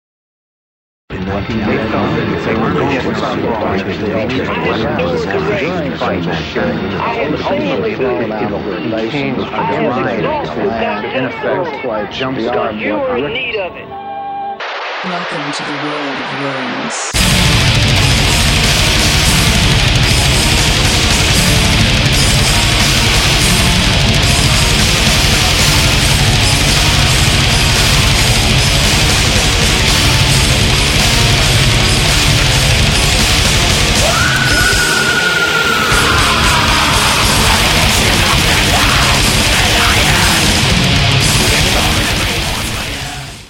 The guitar and bass were played to the nines.